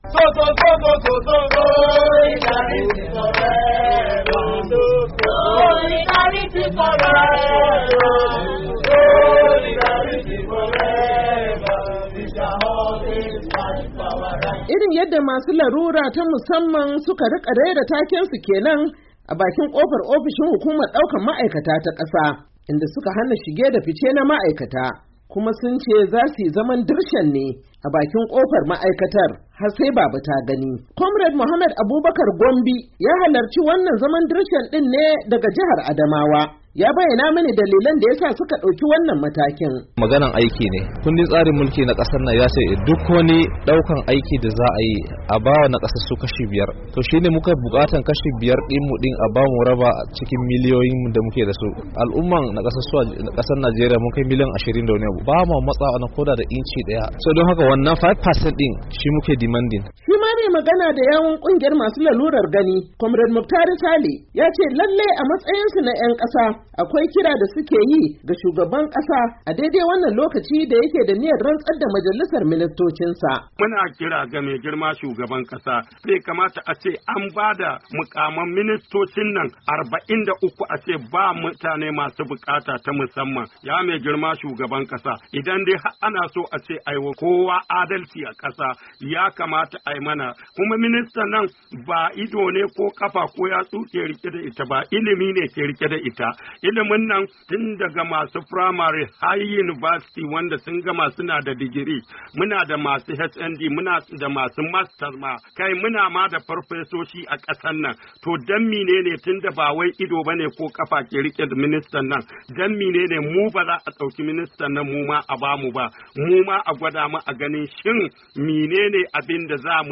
Ga cikakken rahoton